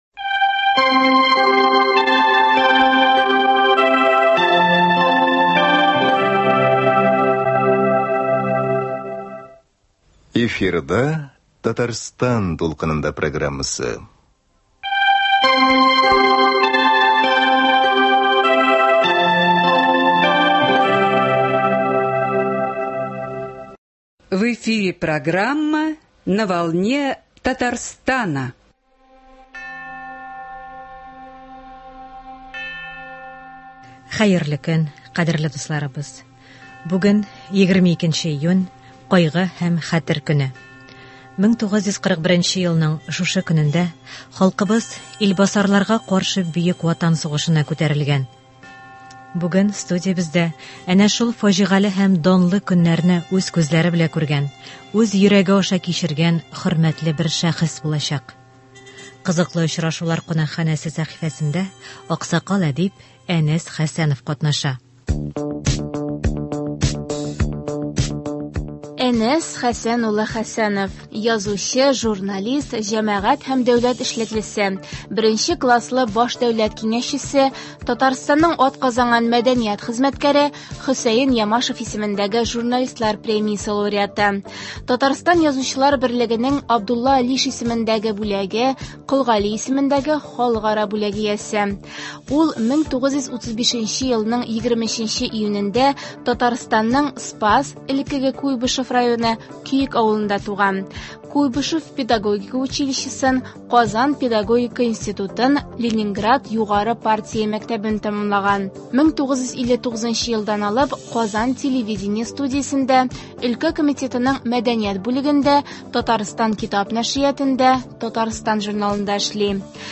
Бүген студиябездә әнә шул фаҗигале һәм данлы көннәрне үз күзләре белән күргән, үз йөрәге аша кичергән хөрмәтле бер шәхес булачак.